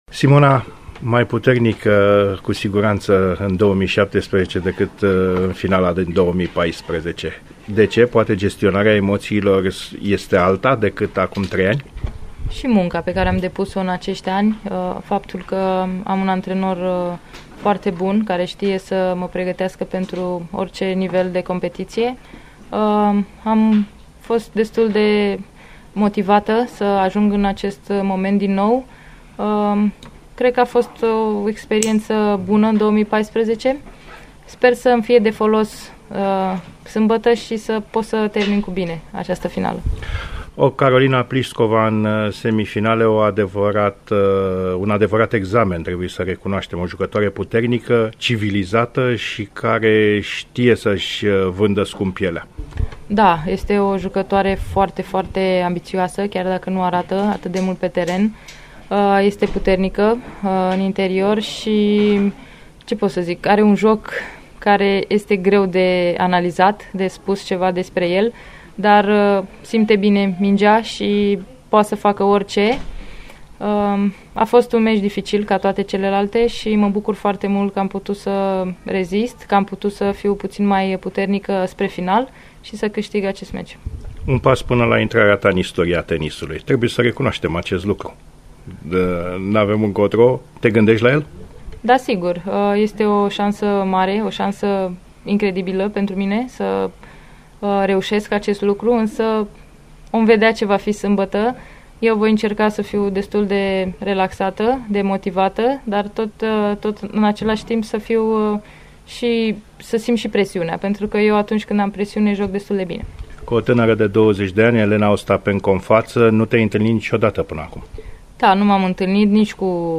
interviu-Simona-Halep-1.mp3